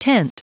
Prononciation du mot tent en anglais (fichier audio)
Prononciation du mot : tent